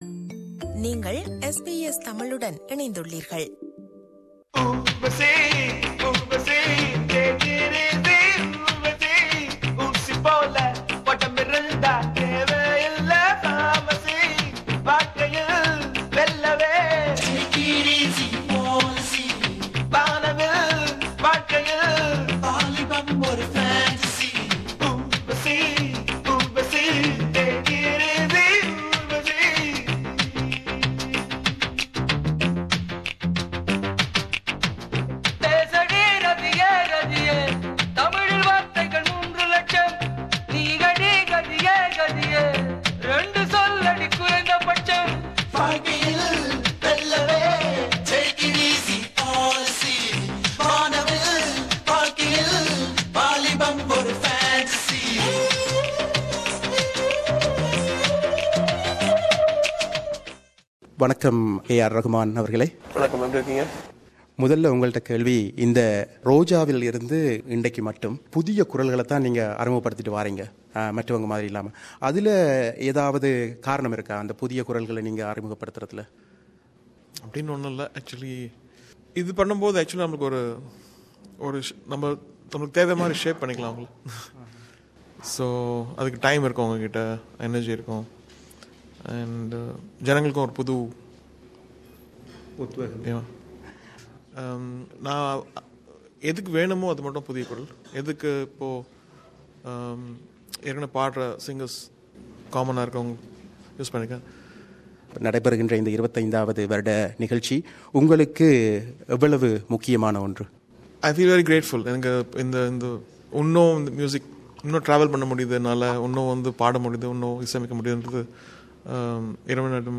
இசைப்புயல் A R ரகுமான் எமக்கு வழங்கிய நேர்முகம் - பாகம் 1